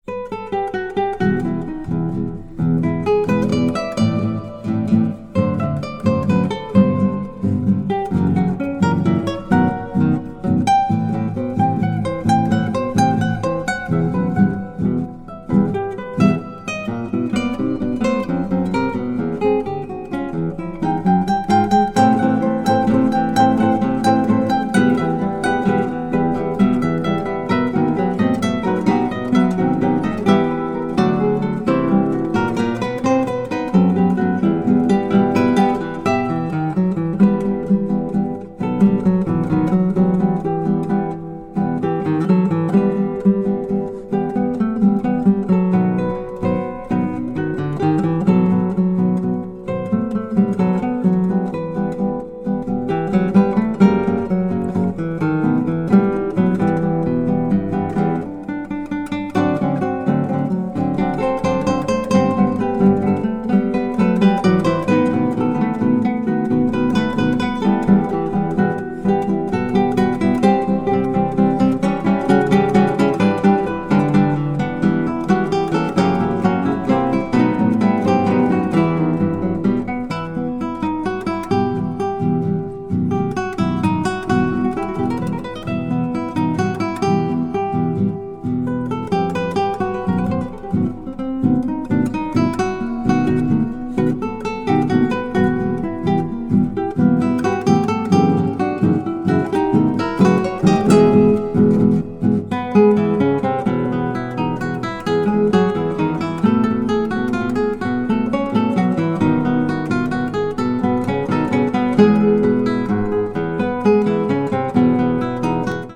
中米的なトロピカルなムードに貫かれた風通しの良い作品！
全編を貫く中米的なトロピカルなムードが、静けさに彩りを添えていて、非常に風通しの良い作品となっています！
南国に吹く微風を想起させる心地良い作品ですよ！